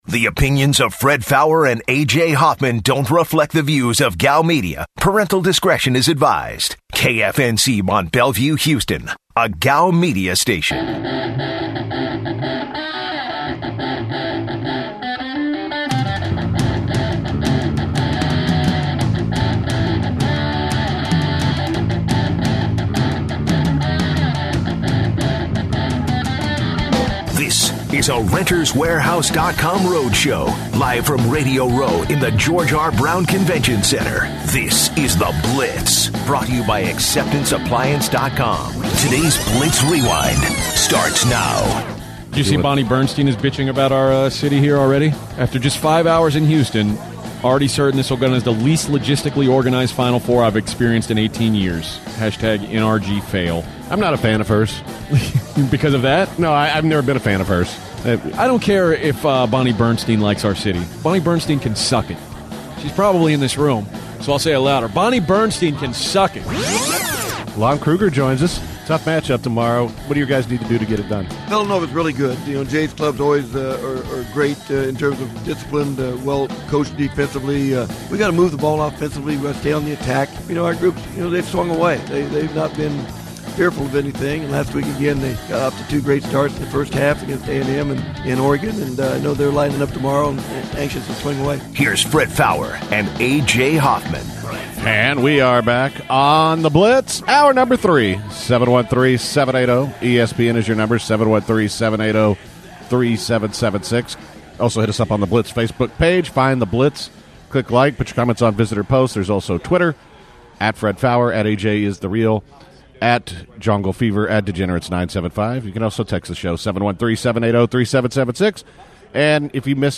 live from Westwood One Radio Row in Downtown Houston.